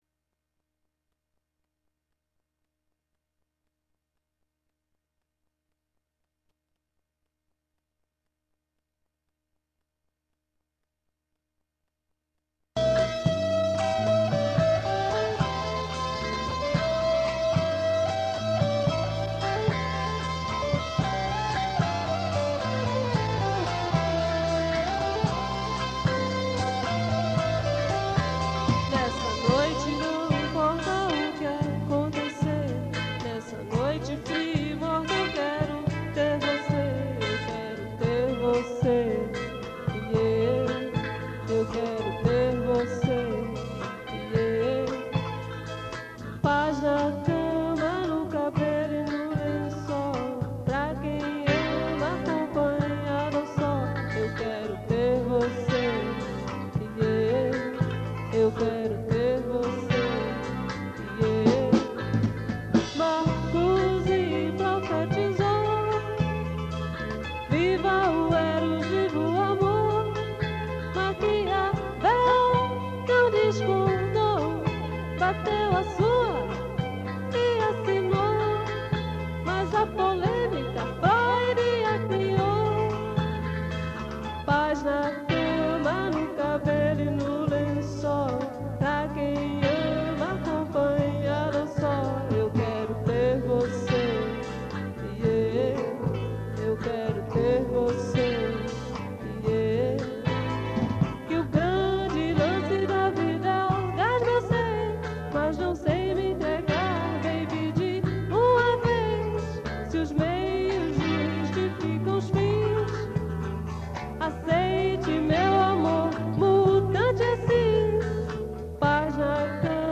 Gravação caseira